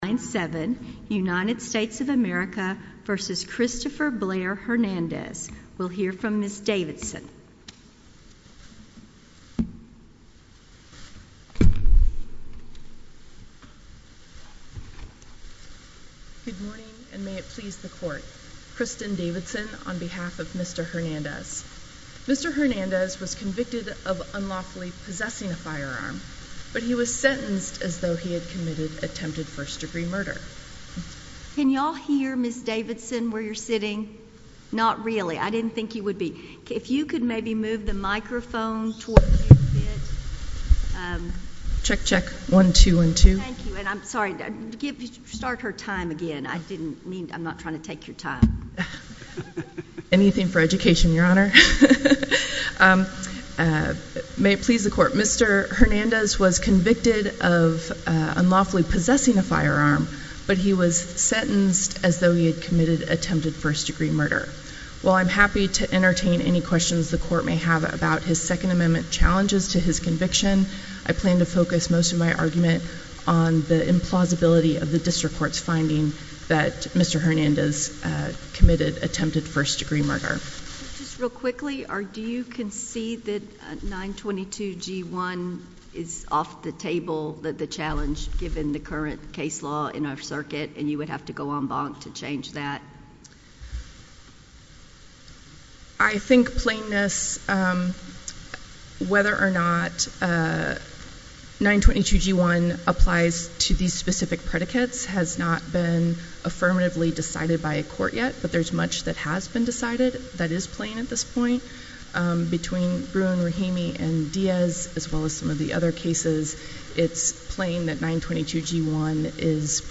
A chronological podcast of oral arguments with improved files and meta data.